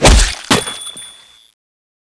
xmas_stab.wav